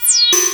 HPF FX.wav